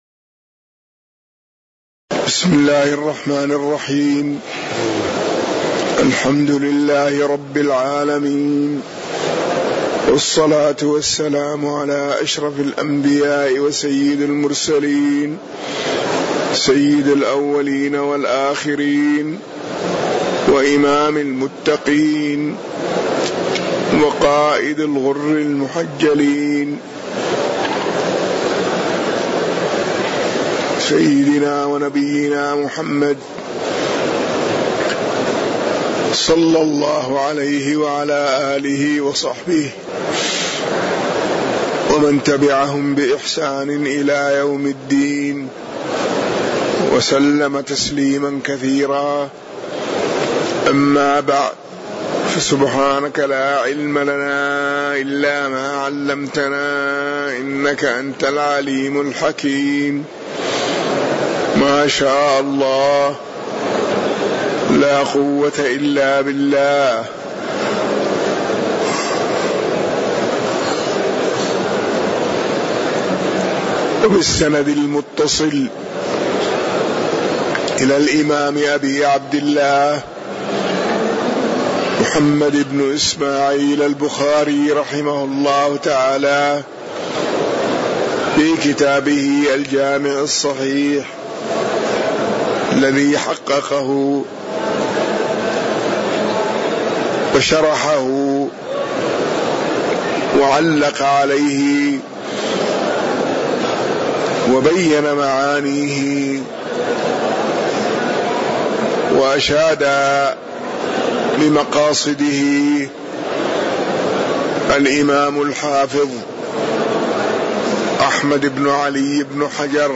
تاريخ النشر ٢٢ شعبان ١٤٣٩ هـ المكان: المسجد النبوي الشيخ